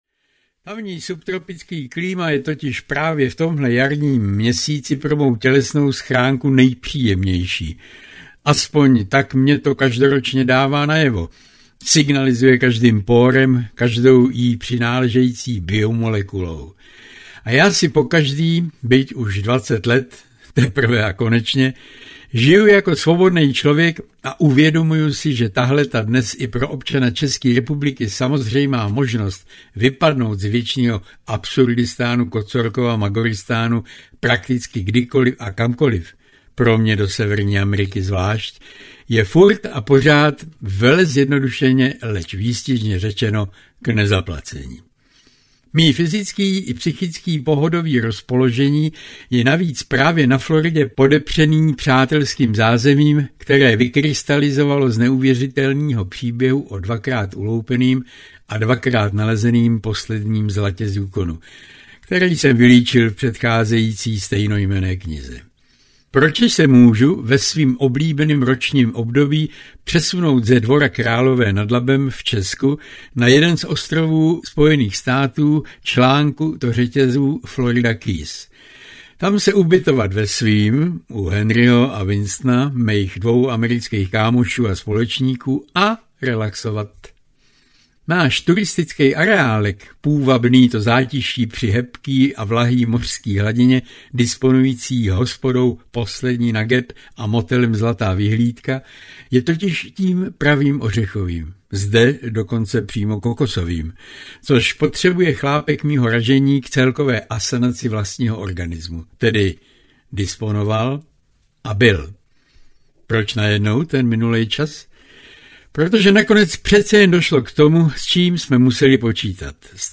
Zlatá hora audiokniha
Ukázka z knihy